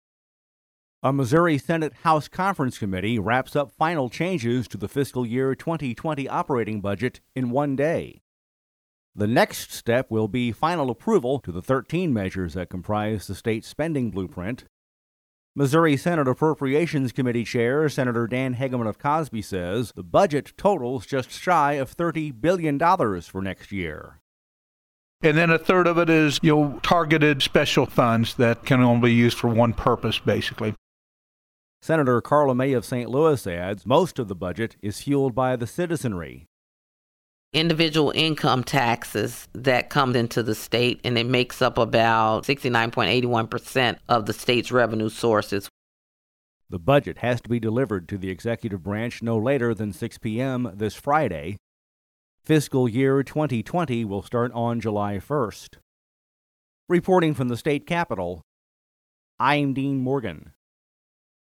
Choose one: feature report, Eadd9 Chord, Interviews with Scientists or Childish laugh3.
feature report